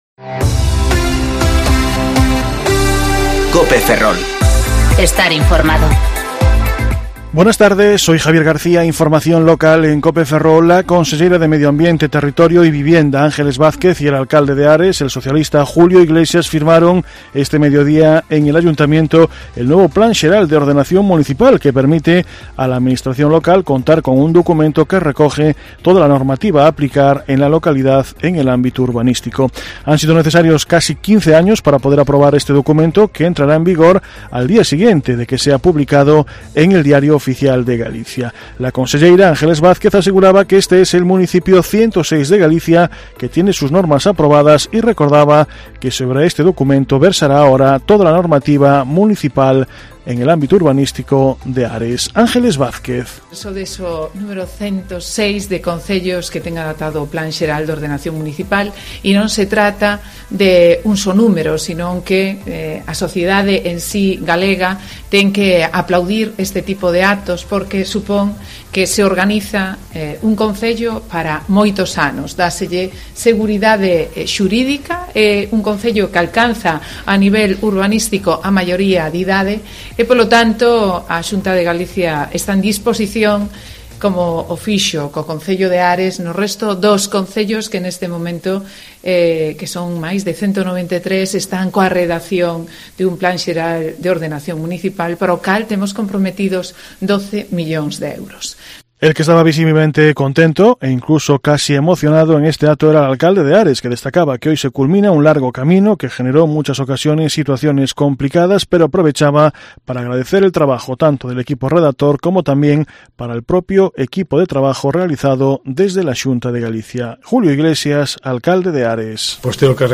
Informativo Mediodía COPE Ferrol 5/2/2020 (De 14,20 a 14,30 horas)